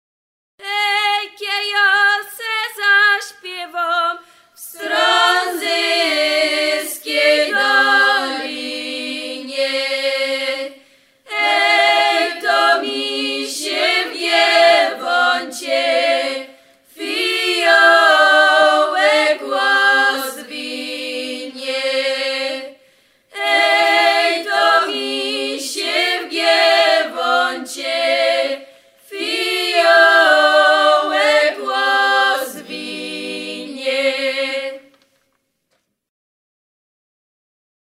�piewki g�ralskie.
�piew ;